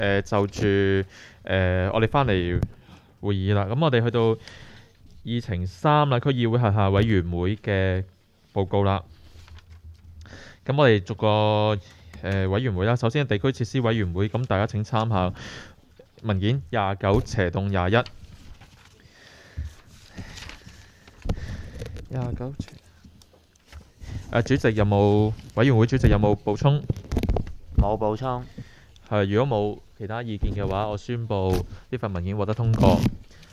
区议会大会的录音记录
深水埗区议会会议室